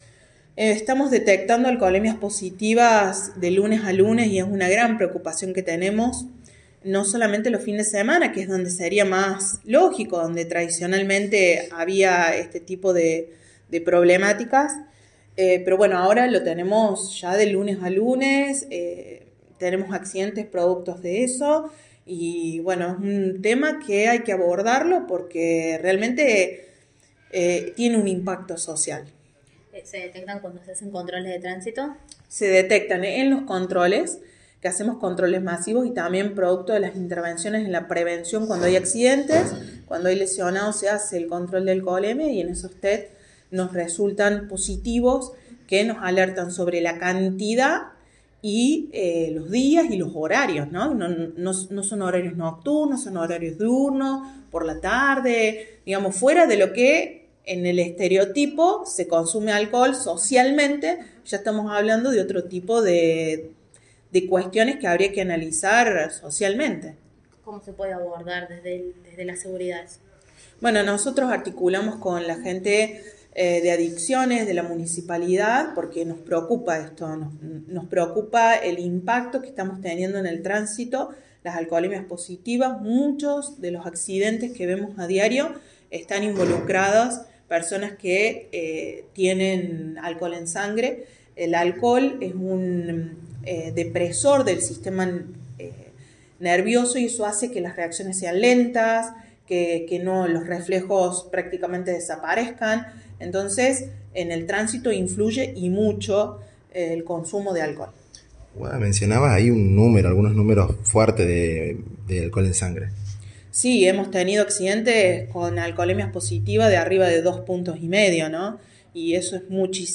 Todos los días y a cualquier hora se detectan conductores con alcohol en sangre durante los controles que hace la municipalidad junto con fuerzas de seguridad. Así lo informó la secretaria de Seguridad de la comuna, Guadalupe Vázquez durante una entrevista.